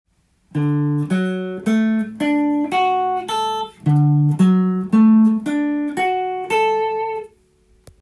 Dコードトーン